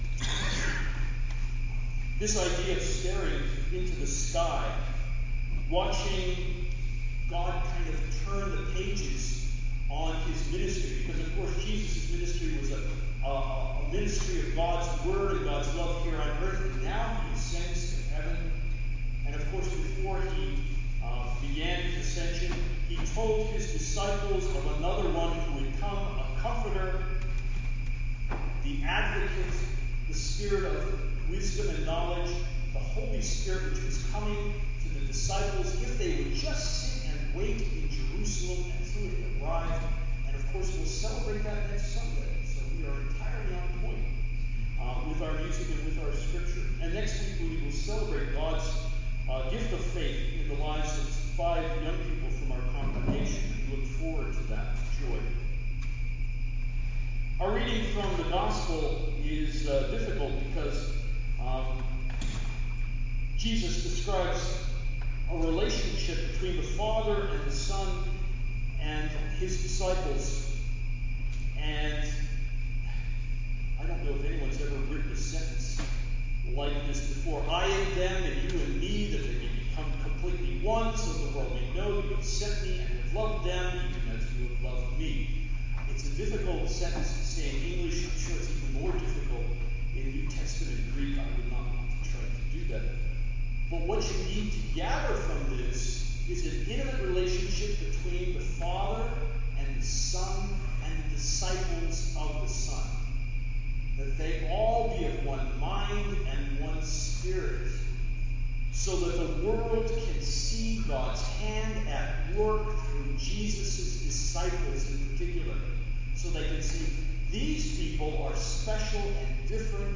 Sermon6-2-CD.mp3